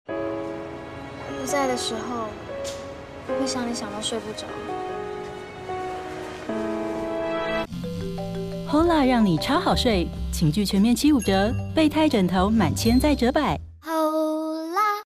國語配音 女性配音員
✔ 聲音具有親和力，極具穿透力與辨識度